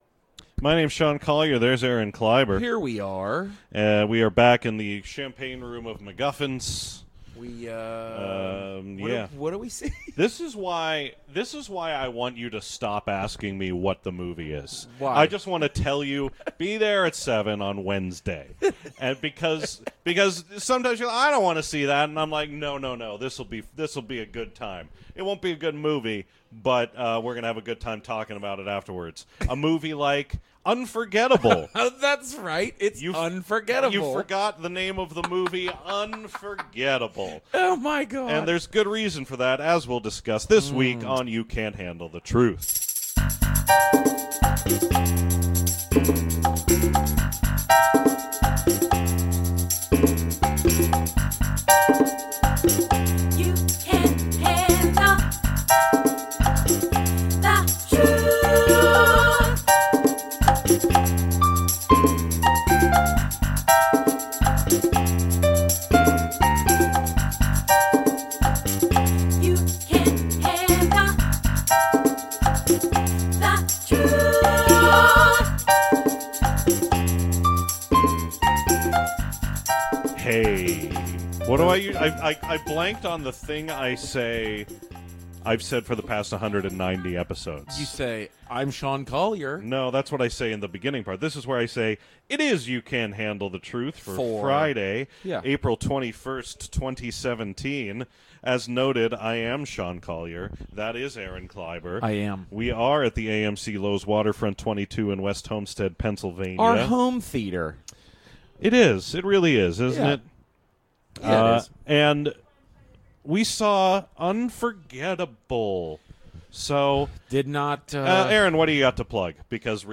Every week we bring you reviews of movies, recorded immediately after an advance screening.